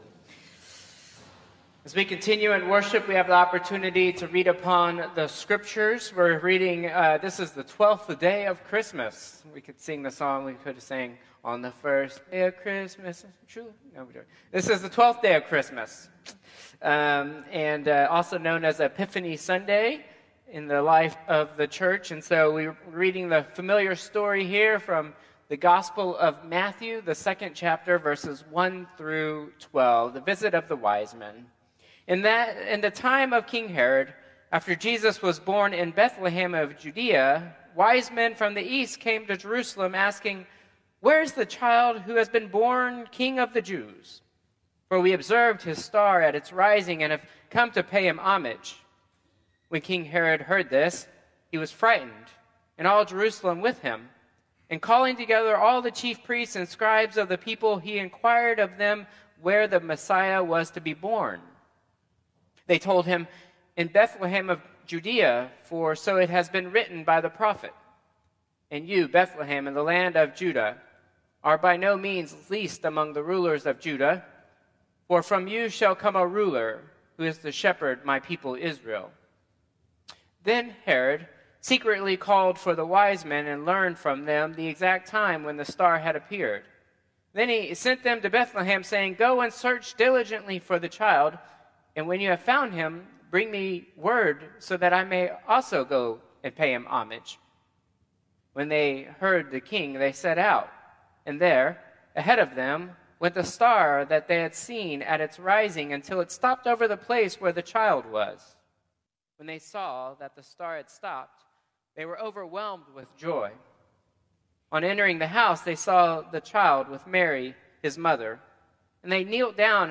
Traditional Service 1/5/2025